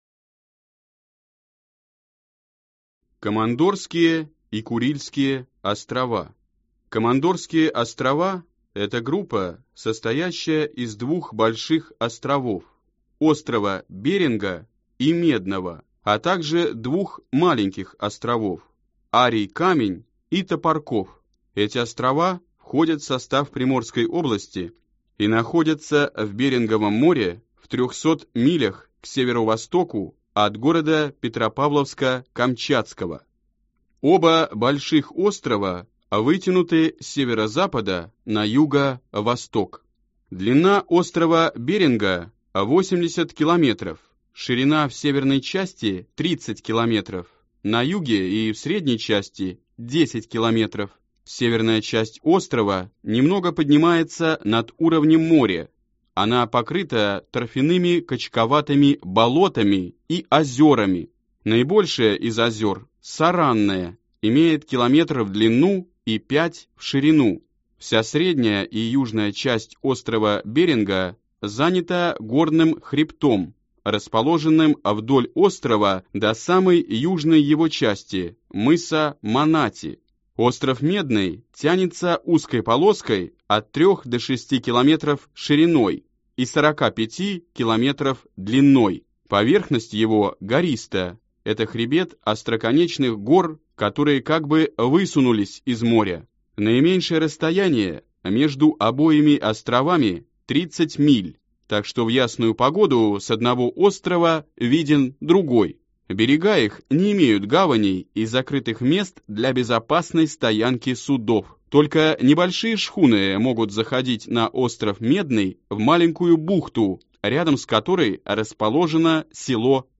Аудиокнига Природа и география России | Библиотека аудиокниг